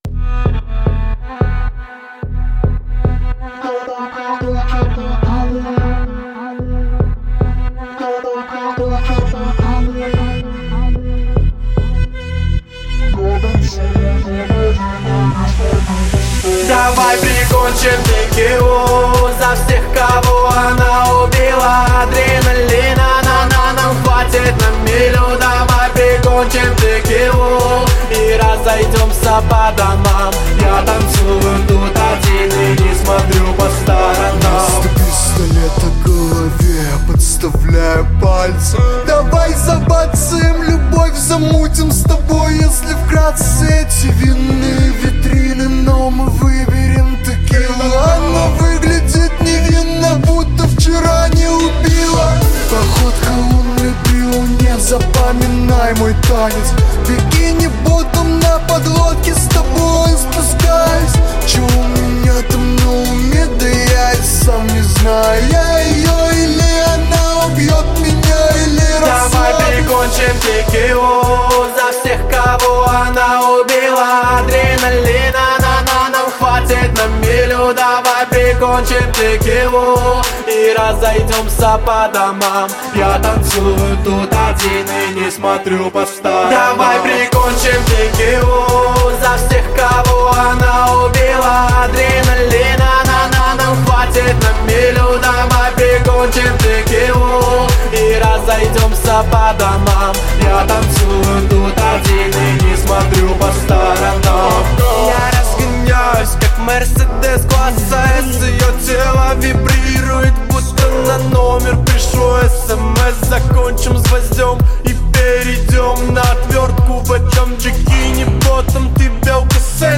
Поп-музыка
Жанр: Жанры / Поп-музыка